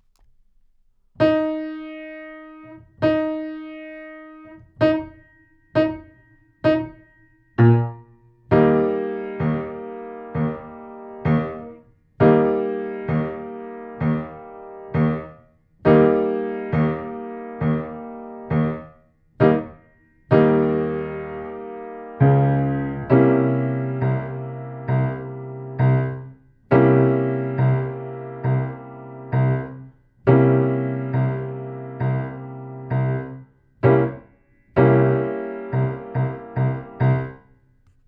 カラオケ音源7inE♭
7_Eb_Te_8bars.WAV